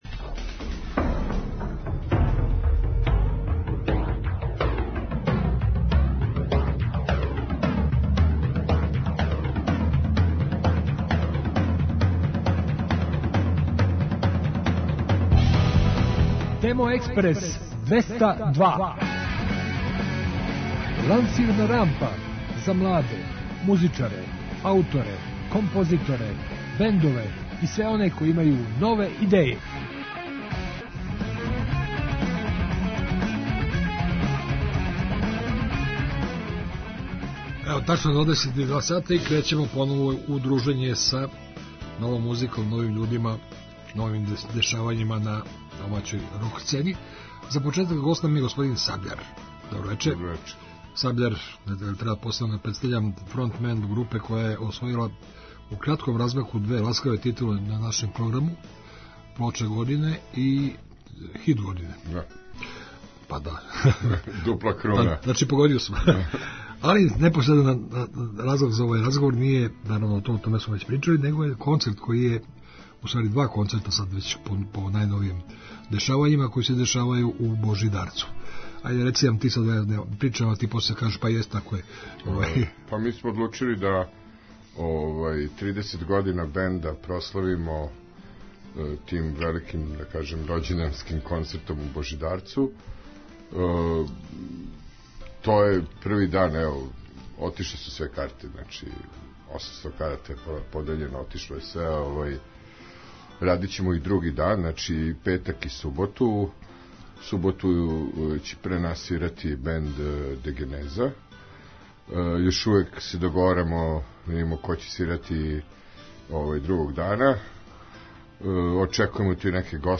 И у овонедељном издању емисије слушамо нове снимке и најављујемо концерте.